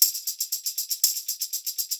120 TAMB1.wav